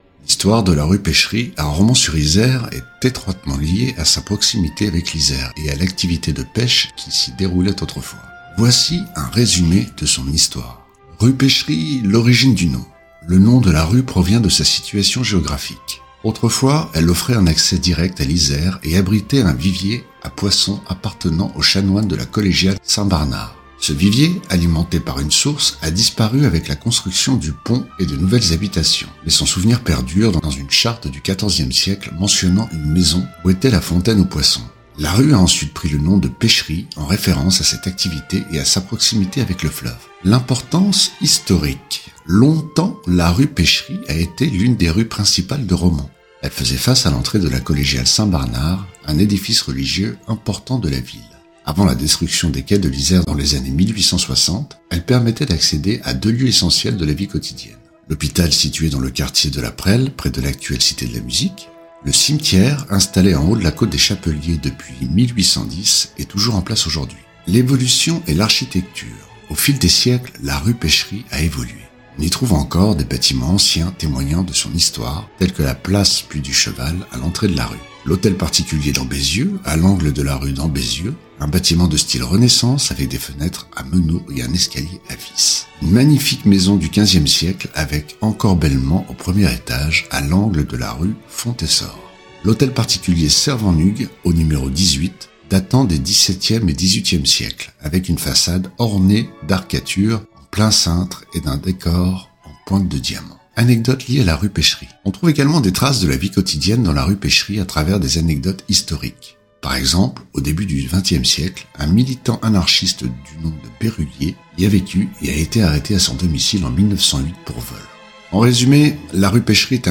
* Musique de fond article mp3 : Symphonie fantastique - Berlioz